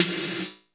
Free MP3 Roland TR606 - Snare drums 2
Snare - Roland TR 29